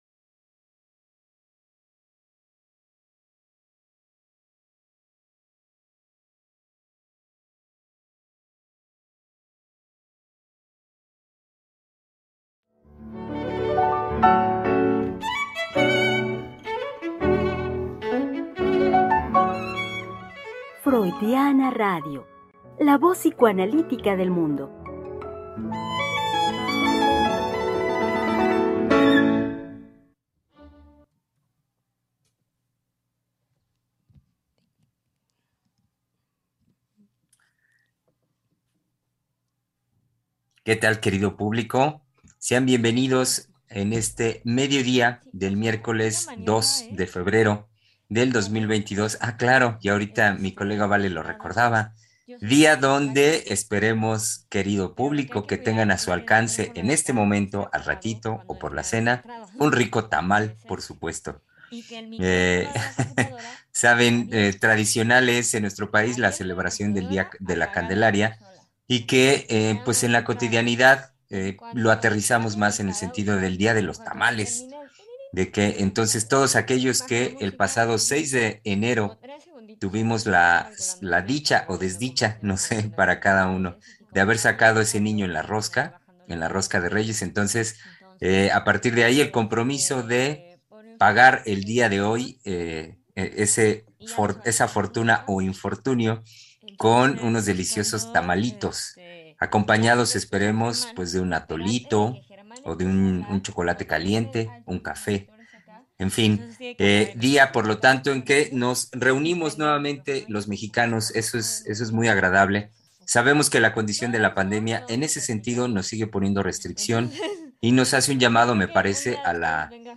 Programa transmitido el 2 de febrero del 2022.